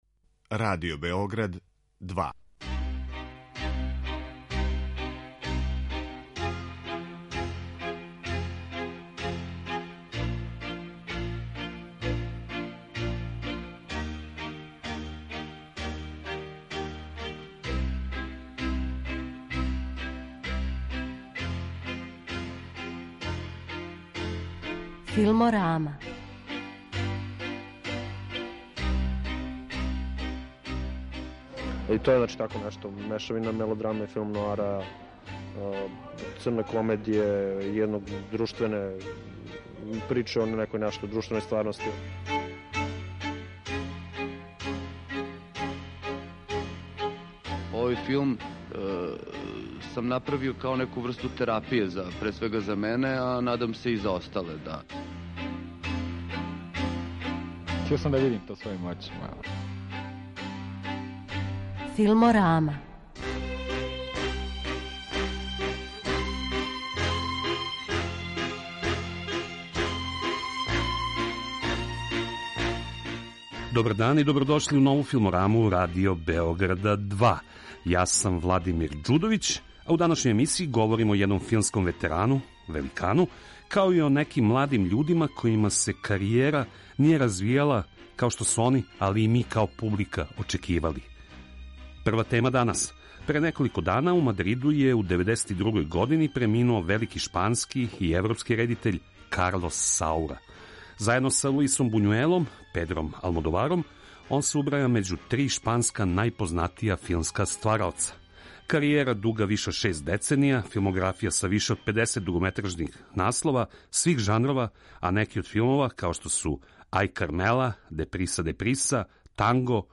У данашњој Филморами емитујемо интервју са њиме, начињен 2017. године, када је Саура био гост београдског ФЕСТ-а.